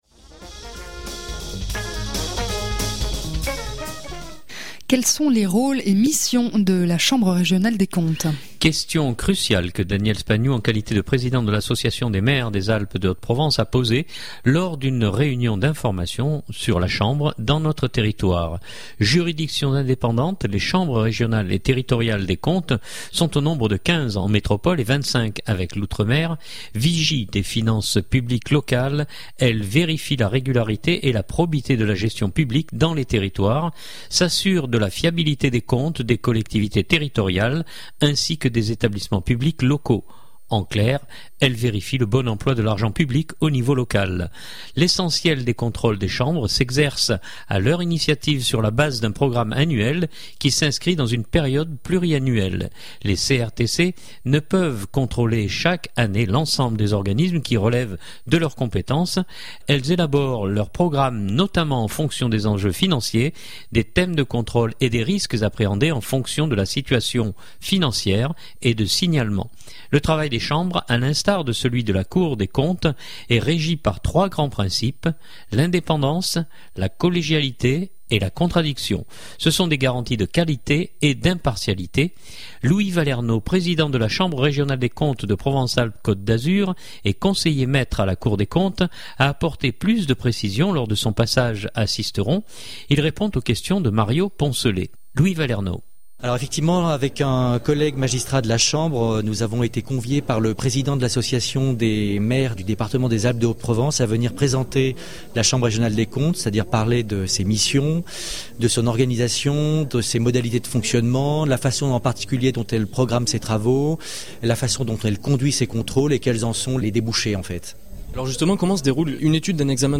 Louis Vallernaud, Président de la chambre régionale des comptes de Provence-Alpes-Côte d'Azur et conseiller maître à la Cour des comptes a apporté plus de précisions lors de son passage à Sisteron.